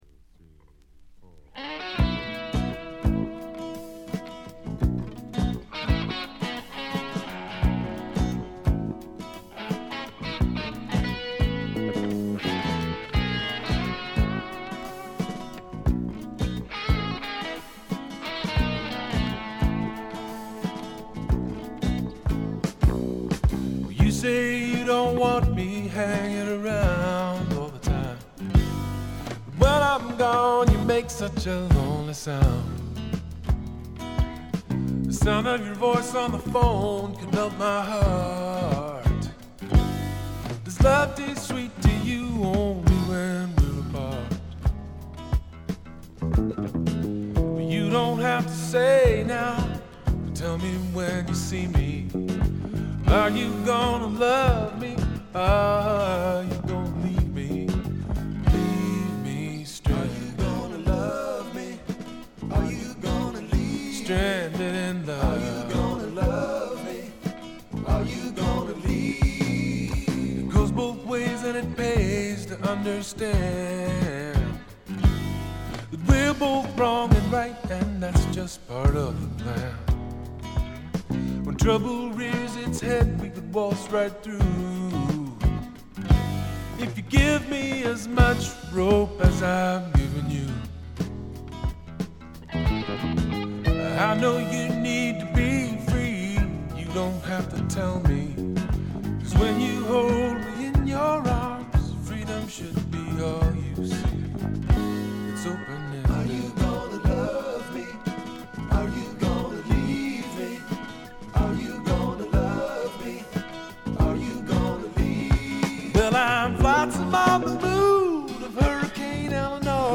試聴曲は現品からの取り込み音源です。
Recorded At - Rex Recording, Portland